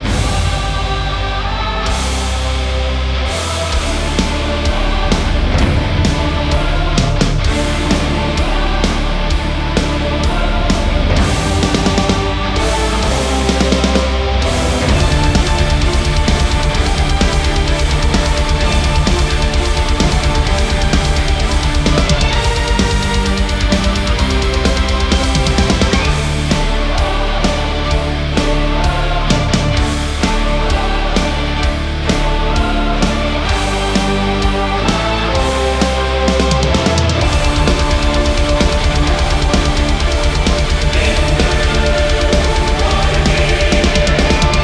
Sympho-Black